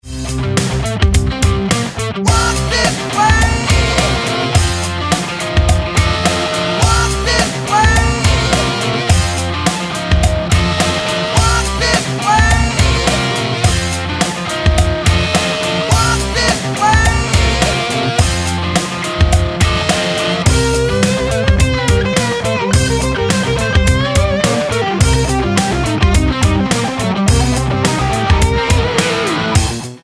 Tonart:C mit Chor